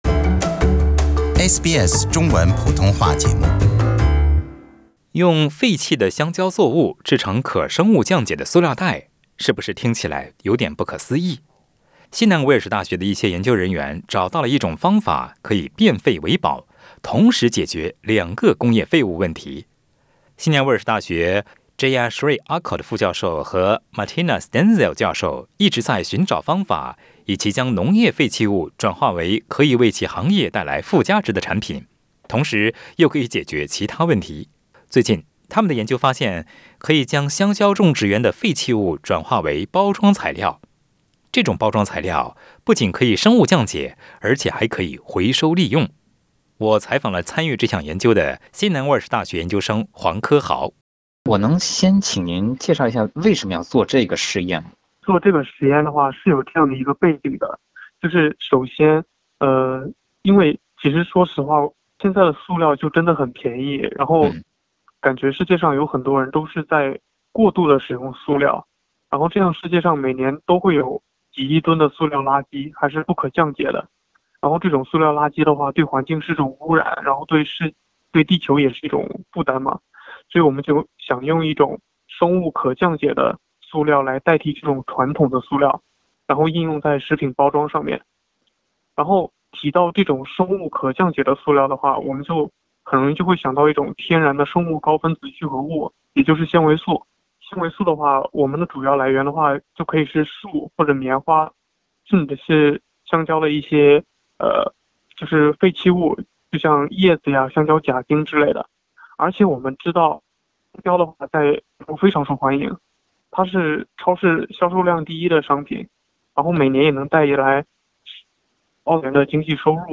请您点击收听详细的采访内容。